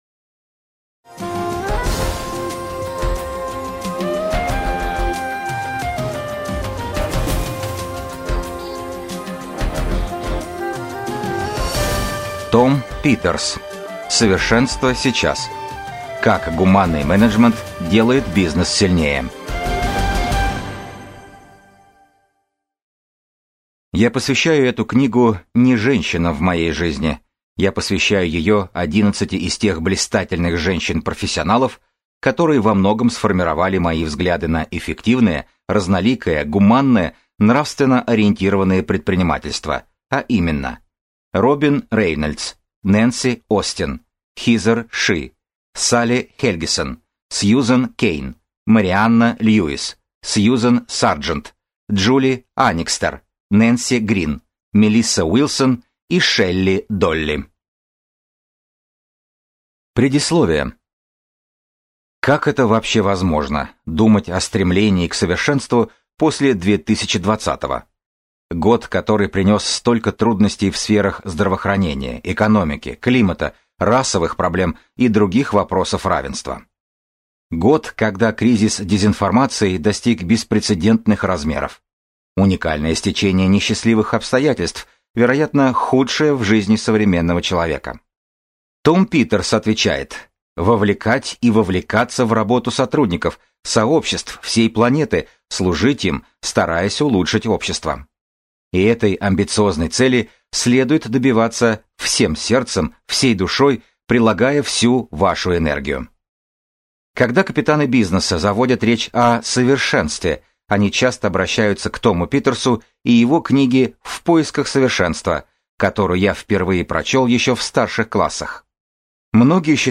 Аудиокнига Совершенство сейчас. Как гуманный менеджмент делает бизнес сильнее | Библиотека аудиокниг